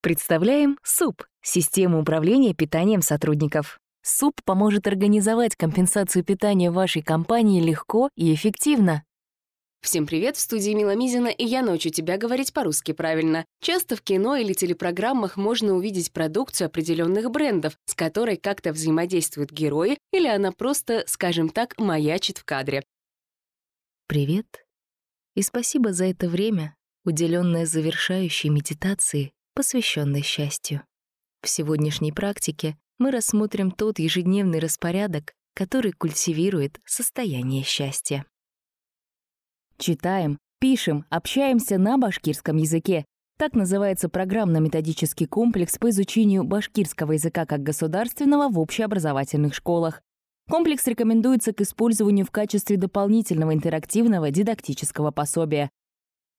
Работаю в различных жанрах - от медитаций до рекламы!
Тракт: Микрофон ARK FET, звуковая карта Audient ID4 MKII, Дикторская кабина